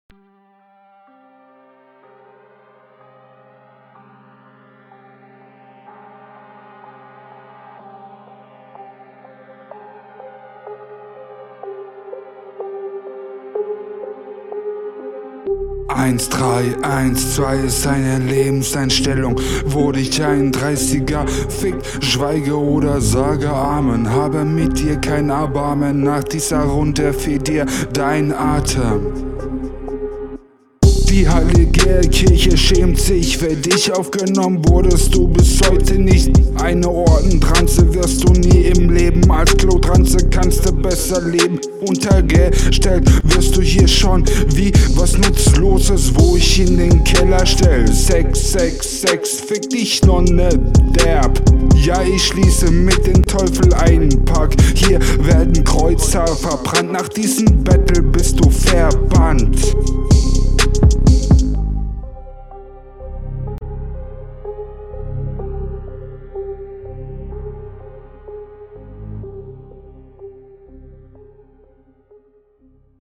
Wieder stark offpoint und kaum Flow vorhanden.